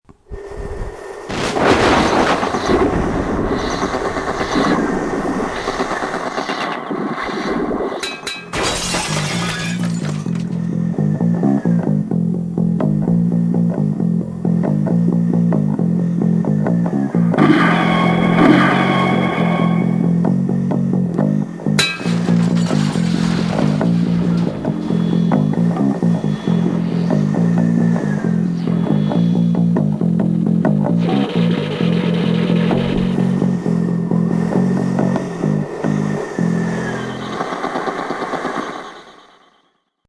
For the full multimedia experience I’ve made a 40 second soundtrack for you to enjoy.
The basics of the soundtrack is a nice riff I once played on my bass guitar. I have recorded it on a rather cheap tape, 10 years ago.
I used the internal microphone of my iBook to record the music cassette I played on my old ghettoblaster. During the process, the tape was a bit bandsaladed.
The funny sounds you hear are produced by the ghettoblaster as well.
The interference of the iBook in action caused a rhythmic distortion.
The “ting” you hear is the sound of a wooden drumstick against an empty 33cl can of Heineken-beer, held by the lip. The blowing of the wind you hear is actually me blowing into and over the same empty beer can in front of the microphone.
The breaking sounds are provided with iMovie, that came with the iBook.